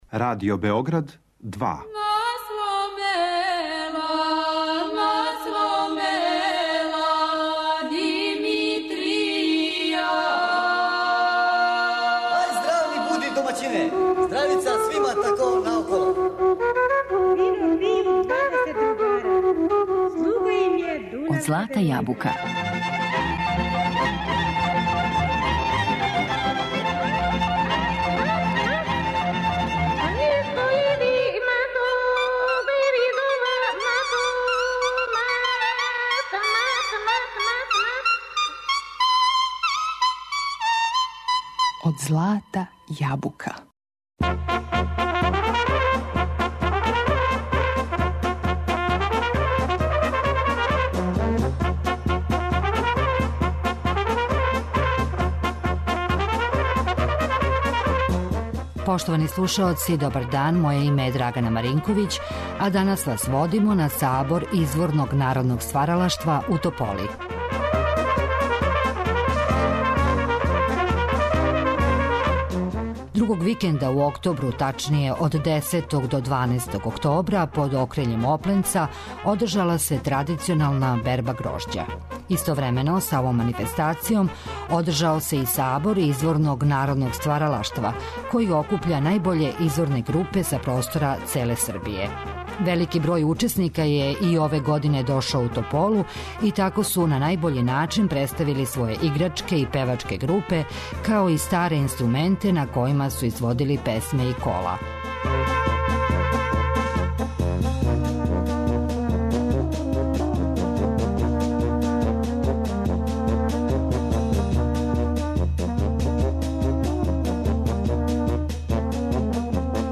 Данас вас водимо на Сабор изворног народног стваралаштва, који је одржан од 10. до 12. октобра у Тополи.
Велики број учесника је на најбољи начин представио своје играчке и певачке групе, као и старе инструменте на којима су изводили песме и кола. У емисији слушамо снимак који смо забележили на овогодишњој манифестацији у Тополи.